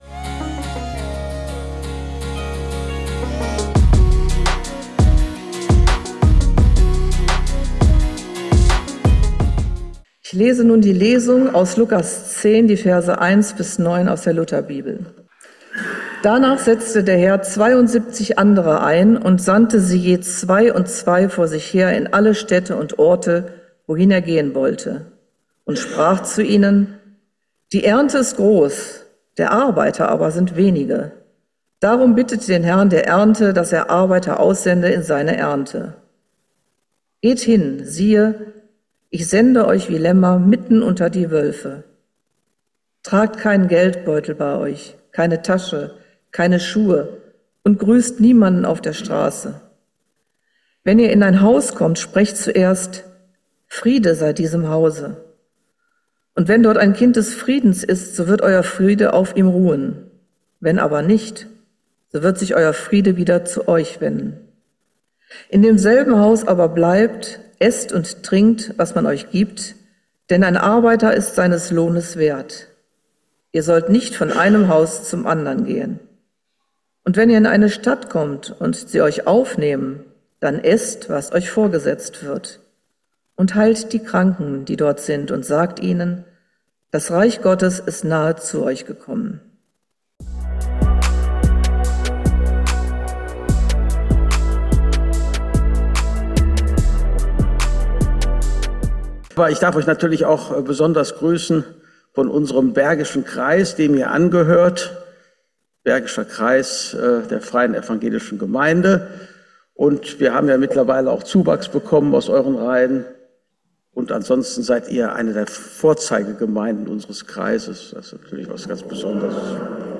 in der Freien evangelischen Gemeinde Wuppertal-Barmen. Er hat anhand der Erlebnisse des Apostels Paulus deutlich gemacht, dass das Evangelium von Jesus Christus regionale und kulturelle Grenzen überwindet.